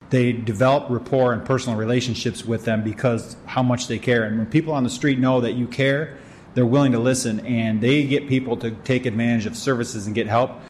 Chief Dave Boysen says their street outreach workers get a lot of the credit because they work directly with young offenders and victims, who are their young brothers and sisters.